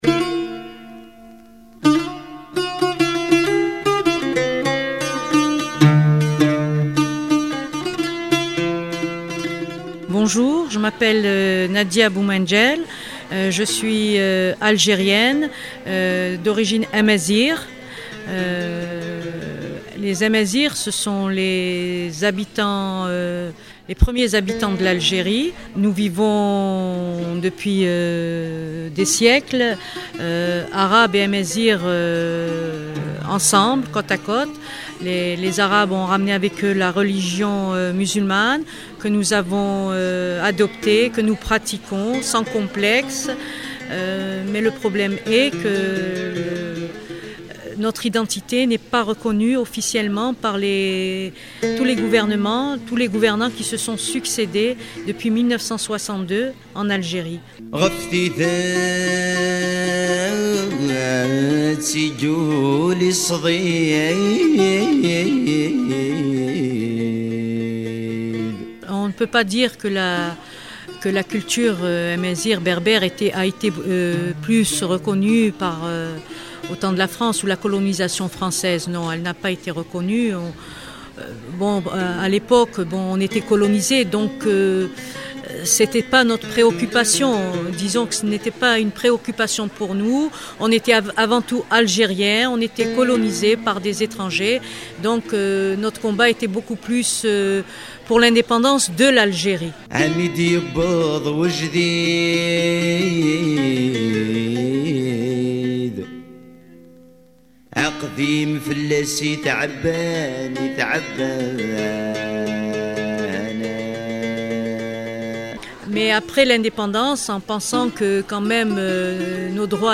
Reportage 9.56'